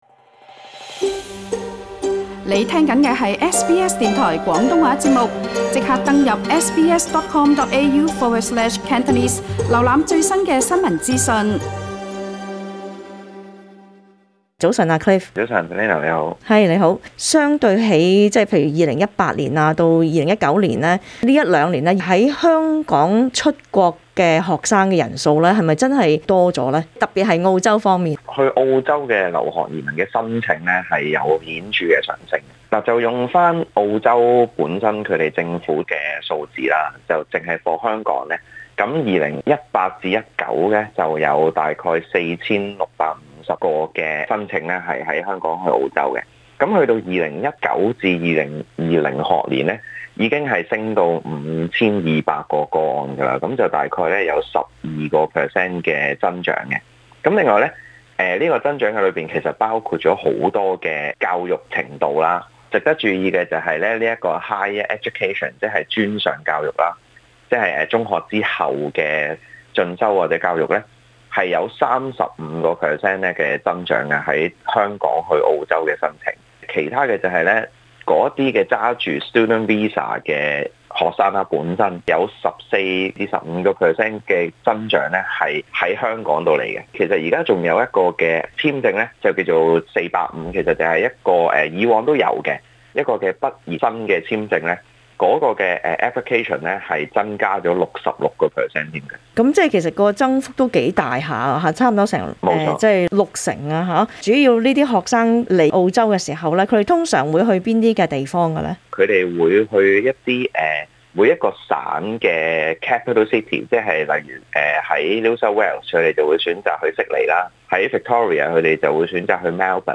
詳情請收聽這節【社區專訪】。